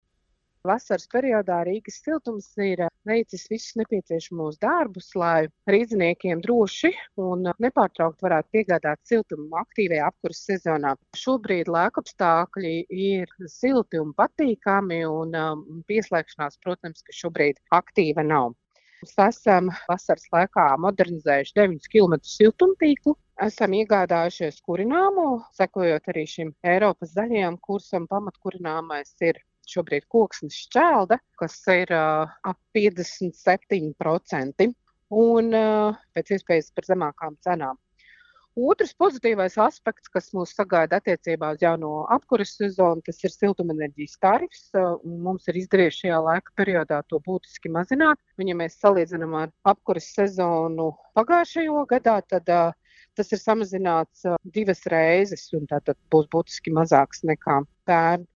RADIO SKONTO Ziņās par gaidāmo apkures sezonu